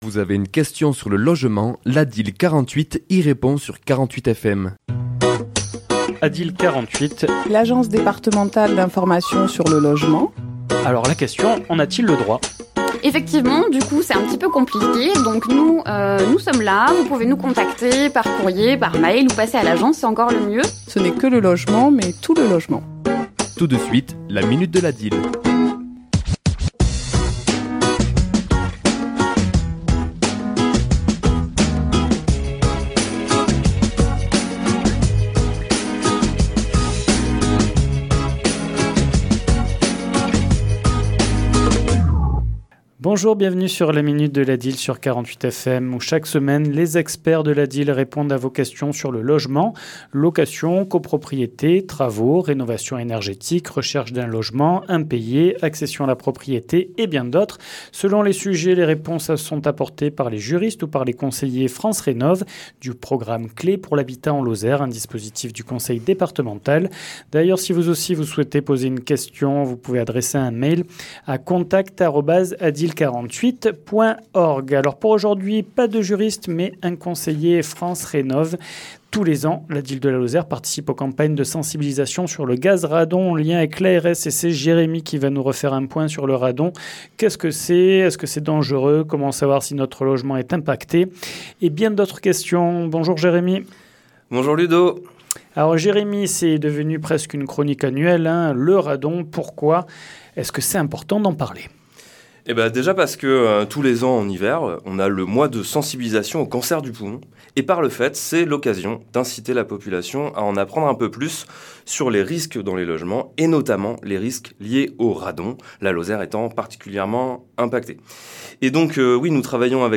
ChroniquesLa minute de l'ADIL
Chronique diffusée le mardi 13 janvier à 11h et 17h10